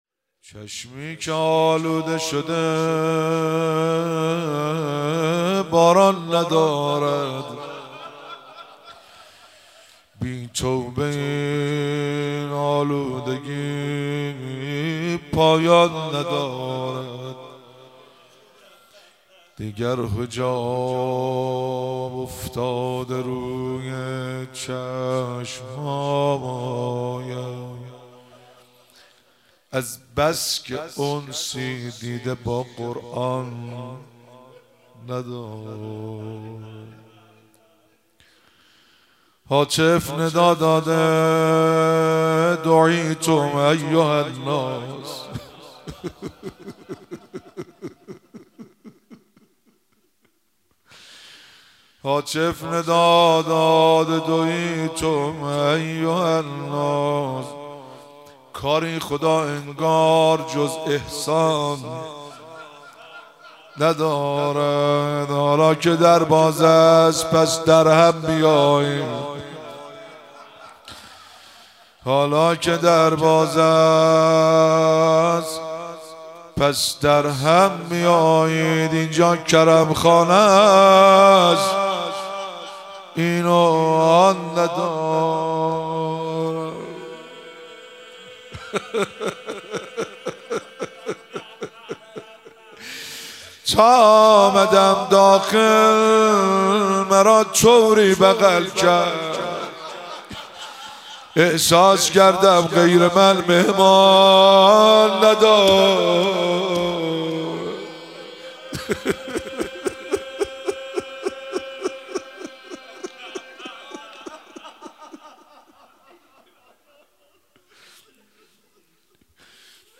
مراسم مناجات خوانی شب اول ماه رمضان 1444